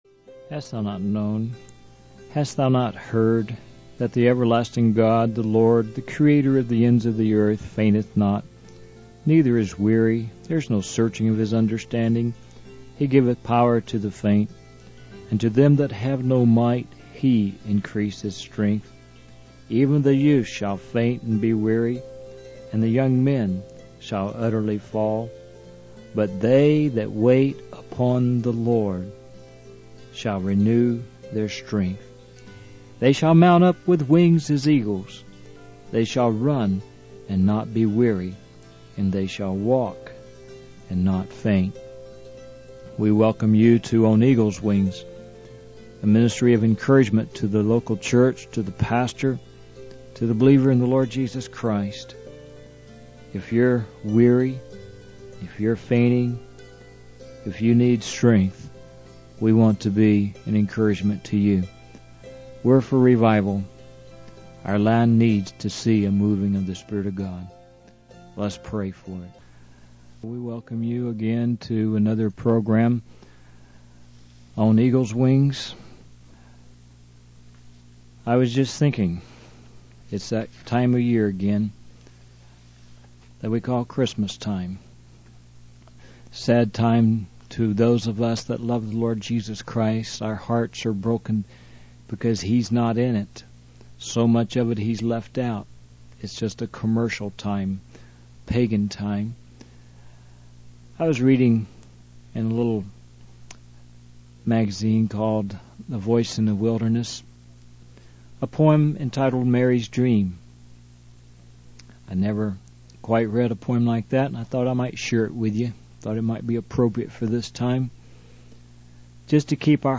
In this sermon, the speaker, a field representative and crusade coordinator, encourages listeners to take the time to share Christ with others, especially during the Christmas season.